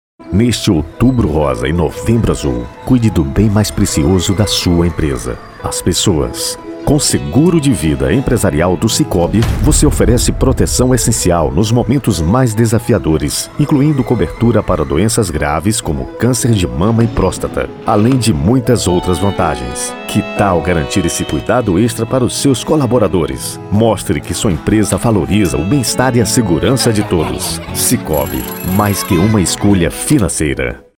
Spot Comercial